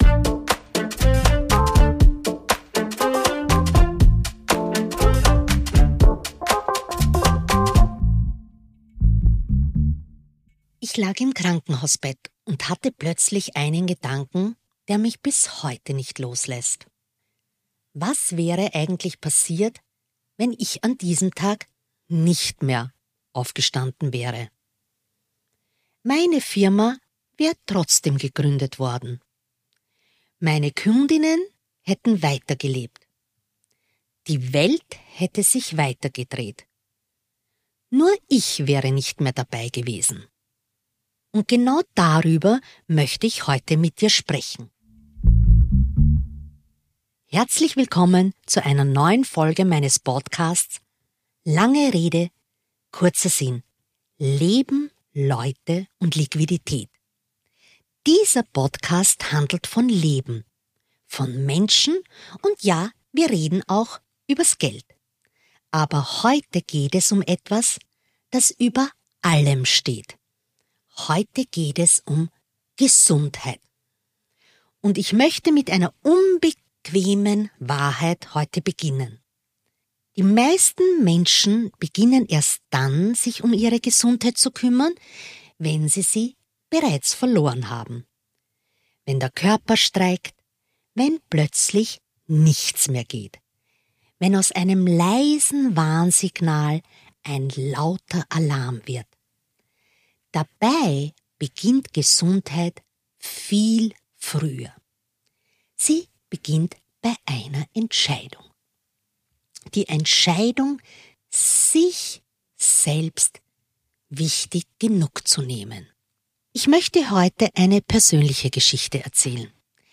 In dieser sehr persönlichen Solofolge spreche ich über einen Moment, der alles verändert hat: Ein Zusammenbruch – genau an dem Tag, an dem mein Unternehmen gegründet wurde.